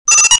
shortwave.ogg